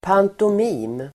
Ladda ner uttalet
Uttal: [pantom'i:m]